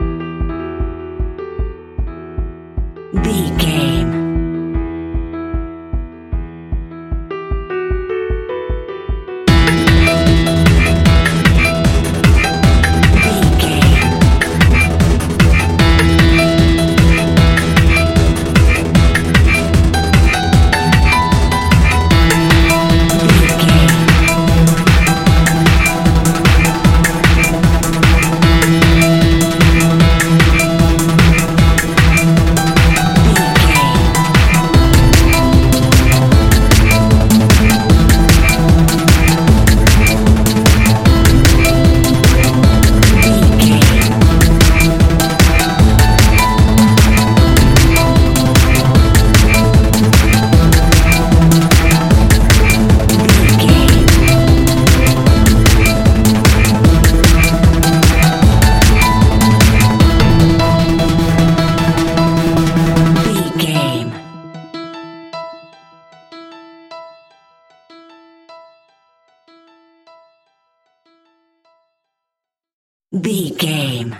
Aeolian/Minor
Fast
futuristic
hypnotic
piano
drum machine
synthesiser
acid house
uptempo
synth leads
synth bass